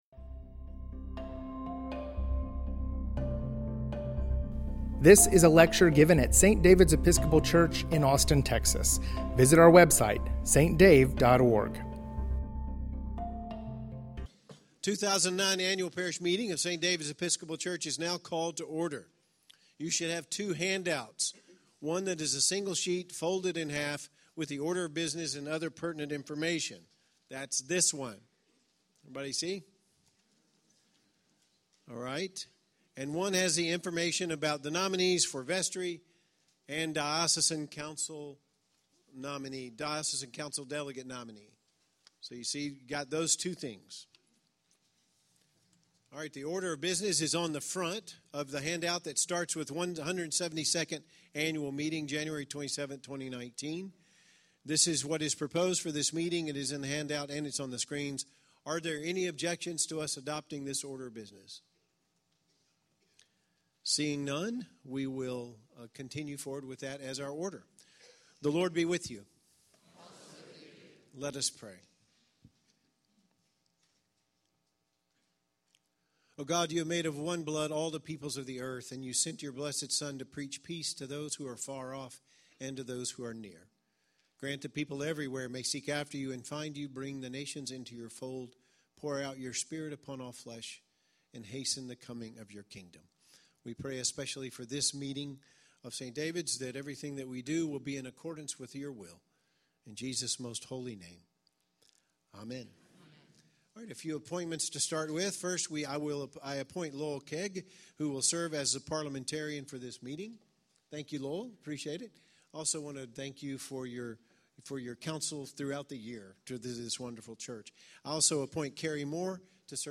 St. Davids "Annual Parish Meeting", January 27, 2019